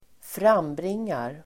Uttal: [²fr'am:bring:ar]
frambringar.mp3